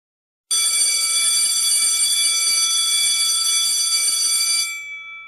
school-bell-ringing-sound-effect.mp3